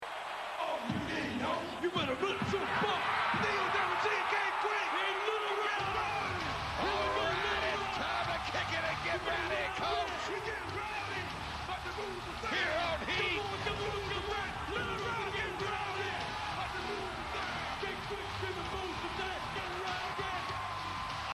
See, towards the end of their five-week run, Road Dogg got noticeably sloppy, both in the ring and
on the mic.